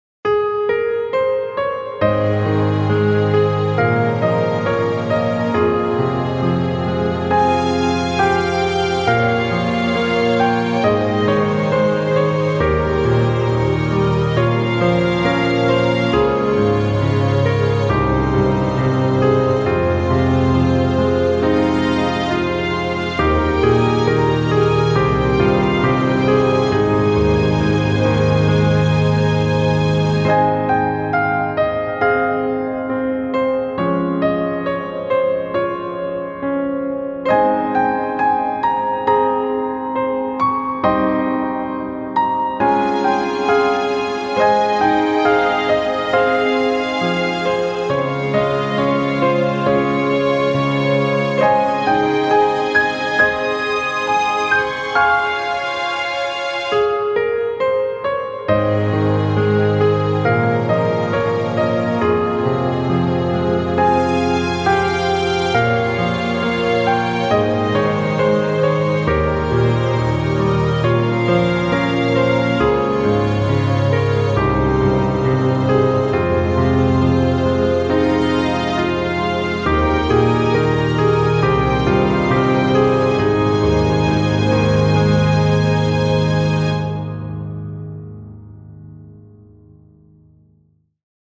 優しい曲調のピアノ中心のヒーリングBGMです。
ピアノ ストリングス バラード ヒーリング
静か 優しい 落ち着く 幻想的 感動 神秘的 穏やか